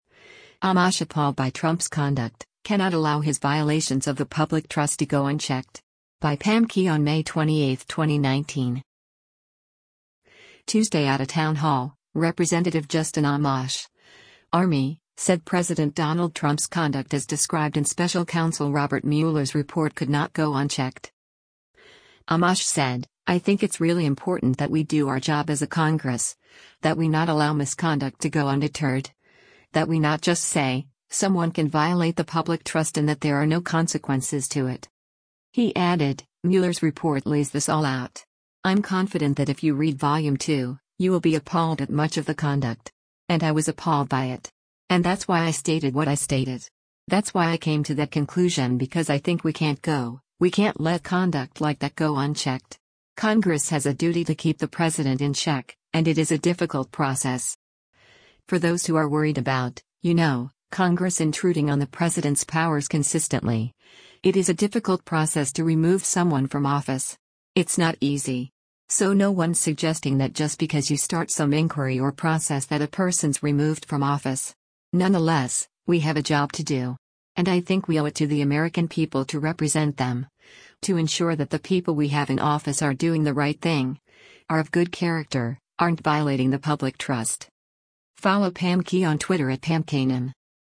Tuesday at a town hall, Rep. Justin Amash (R-MI) said President Donald Trump’s conduct as described in special counsel Robert Mueller’s report could not go unchecked.